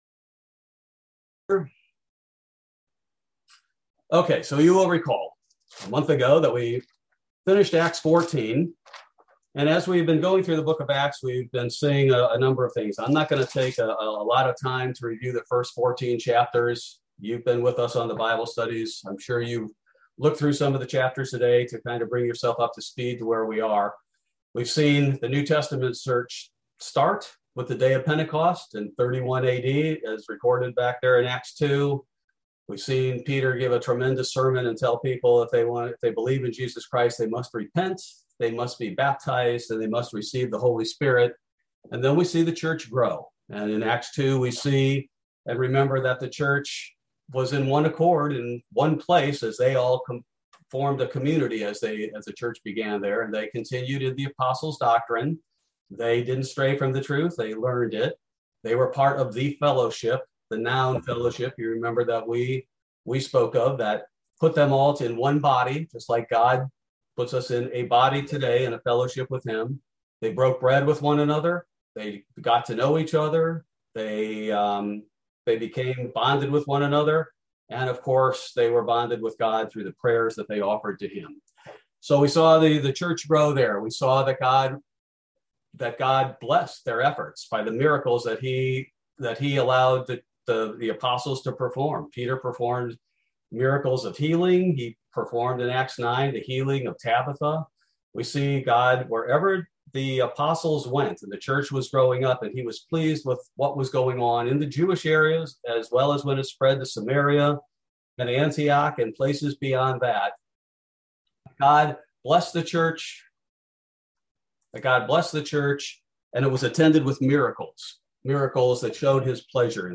Bible Study - October 6, 2021